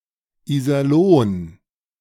Iserlohn (German: [iːzɐˈloːn]
De-Iserlohn.ogg.mp3